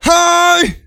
XS长声04.wav
XS长声04.wav 0:00.00 0:00.87 XS长声04.wav WAV · 75 KB · 單聲道 (1ch) 下载文件 本站所有音效均采用 CC0 授权 ，可免费用于商业与个人项目，无需署名。
人声采集素材